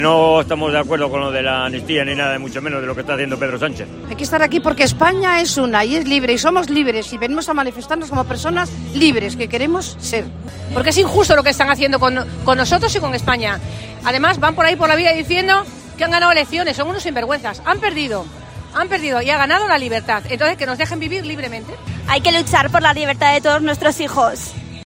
20.000 personas se concentran en la Plaza Pombo